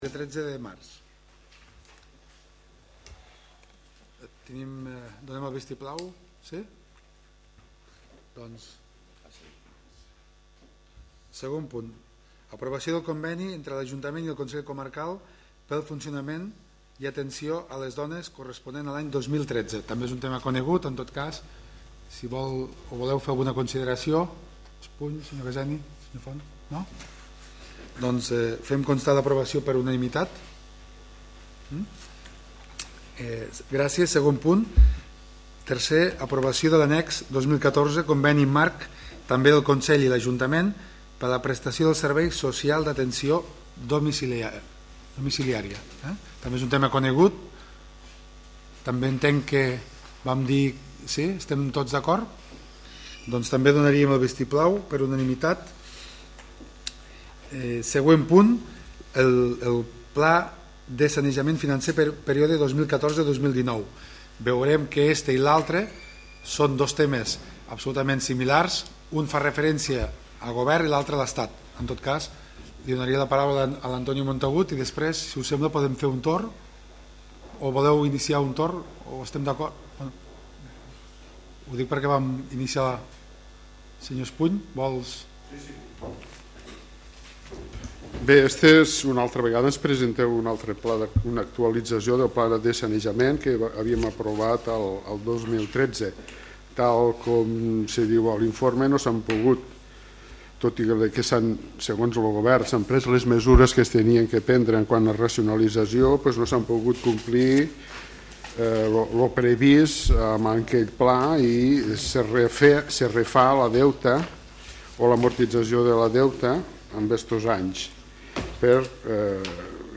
Àudio del Ple de l'Ajuntament del dia 27 de març del 2014